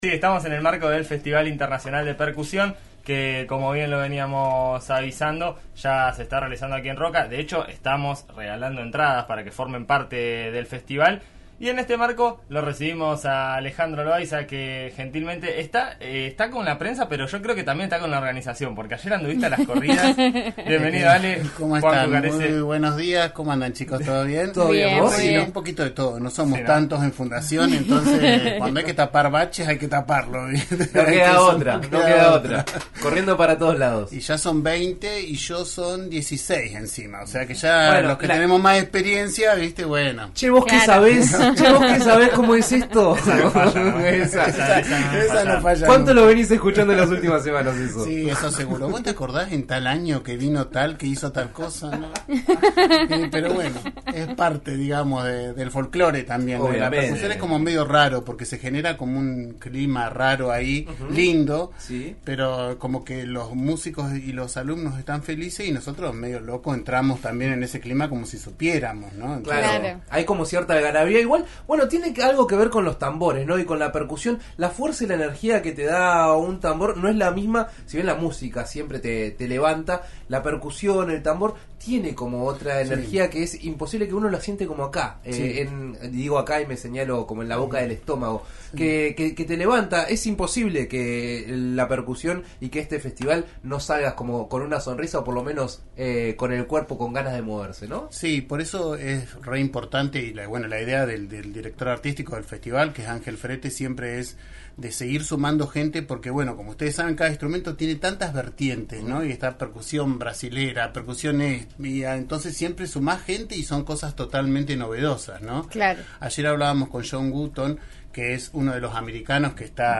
En eso estamos de RN Radio recibió en sus estudios